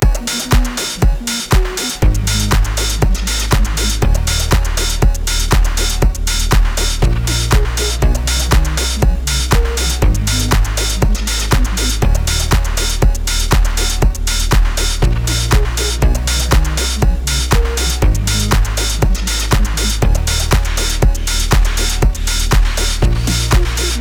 Music - Song Key
Gb Major
Music - Bass
Music - Percussion
Music - Lead
Music - Pad
Aggressive (Cheetah)